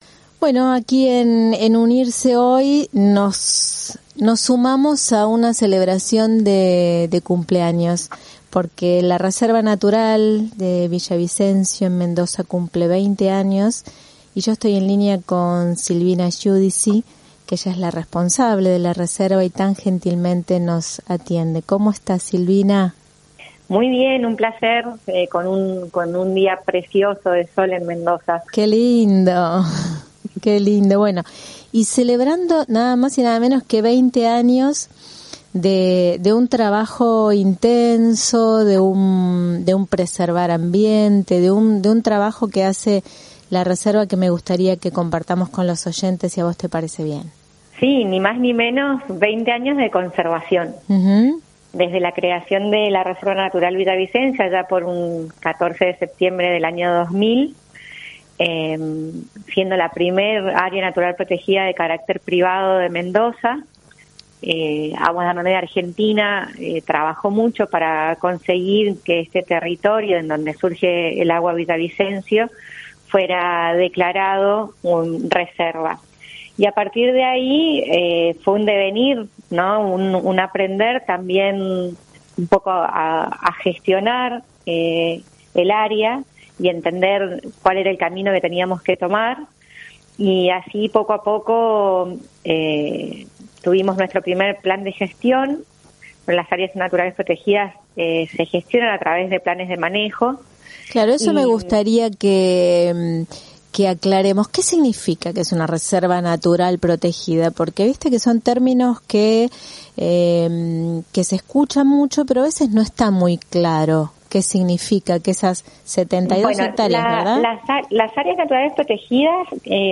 AmbienteEntrevistas